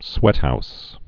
(swĕthous)